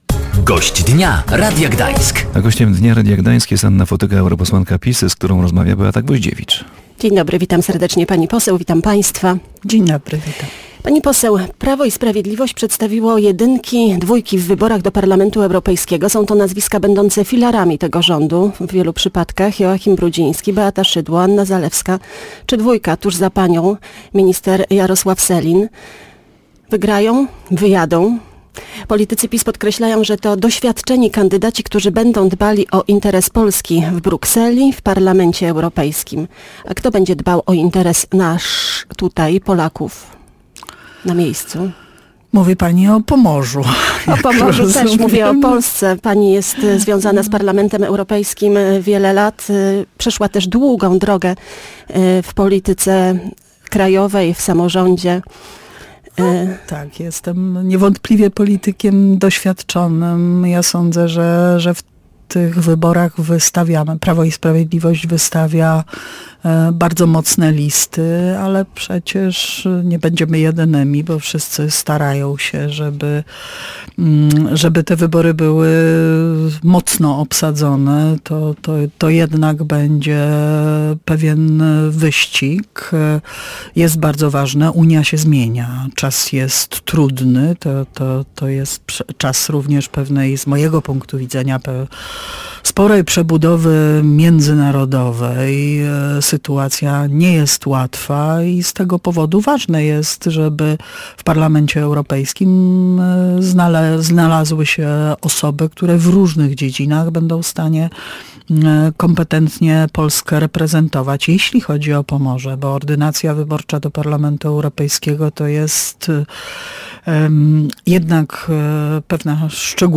Gościem Dnia Radia Gdańsk była europosłanka PiS Anna Fotyga.